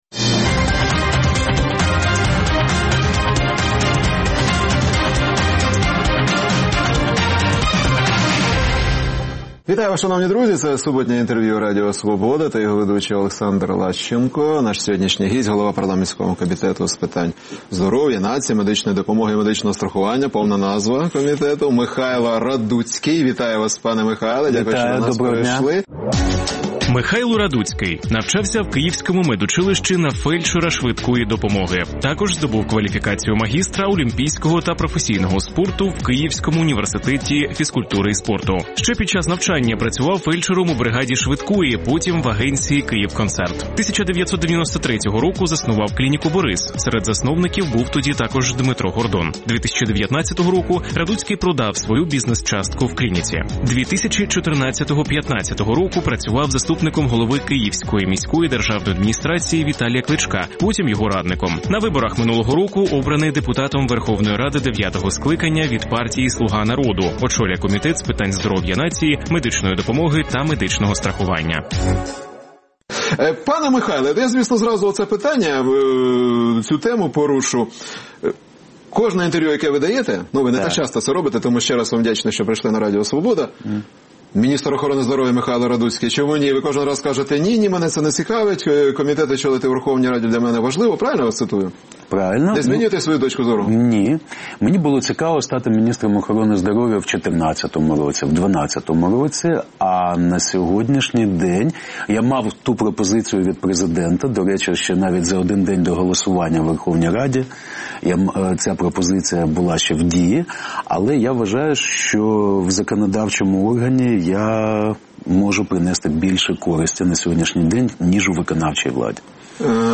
Суботнє інтерв’ю | Михайло Радуцький, голова парламентського комітету з питань здоров'я